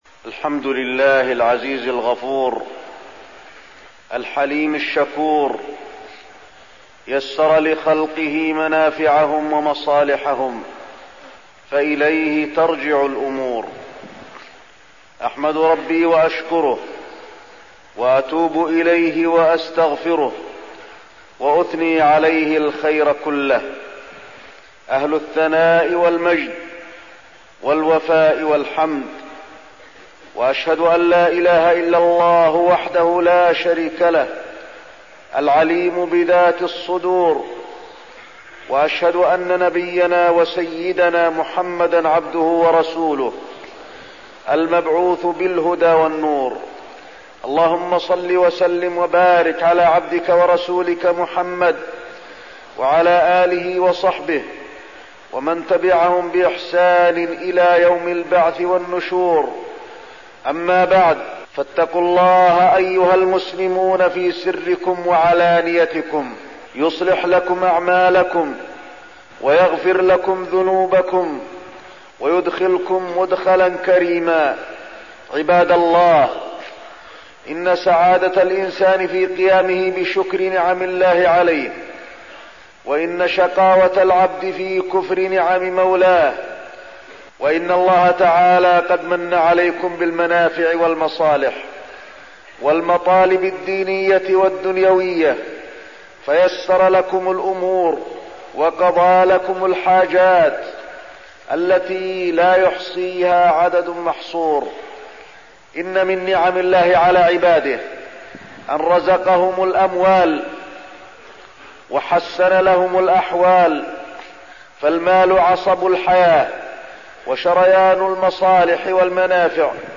تاريخ النشر ٣٠ جمادى الآخرة ١٤١٨ هـ المكان: المسجد النبوي الشيخ: فضيلة الشيخ د. علي بن عبدالرحمن الحذيفي فضيلة الشيخ د. علي بن عبدالرحمن الحذيفي الحقوق في المال